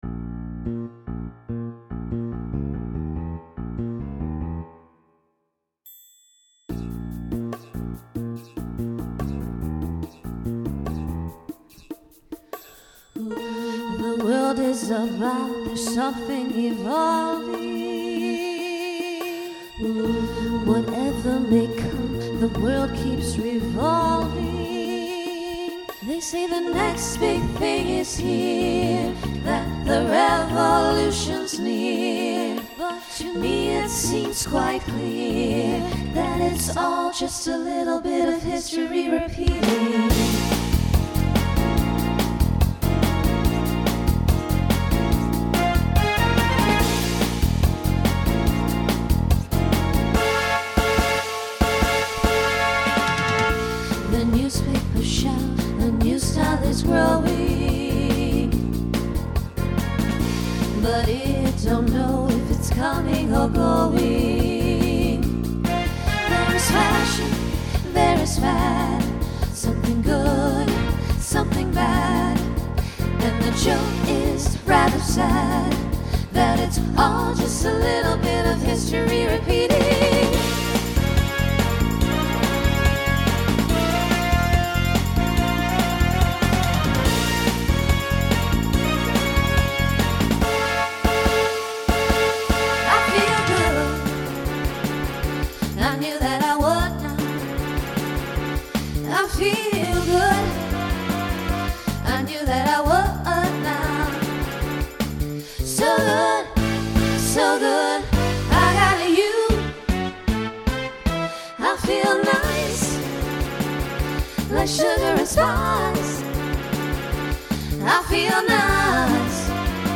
Voicing SSA Instrumental combo Genre Pop/Dance , Rock
Mid-tempo